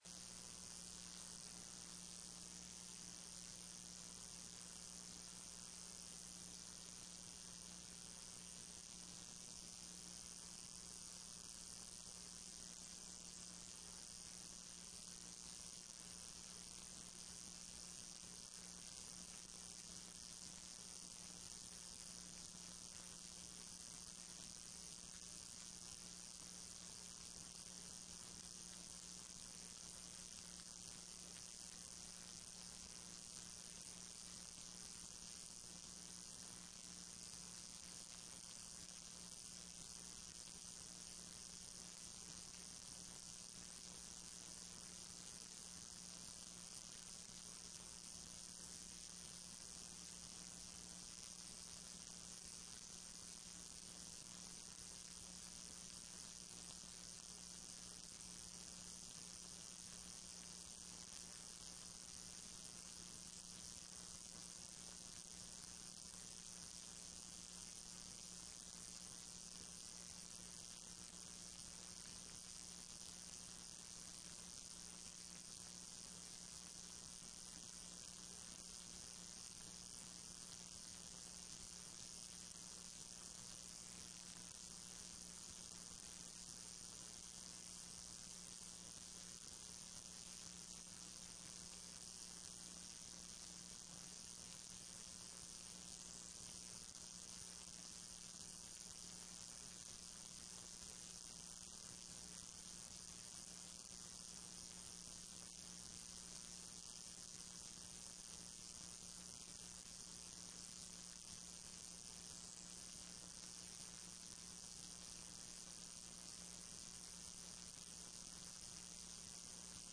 TRE-ES sessão do dia 16 09 14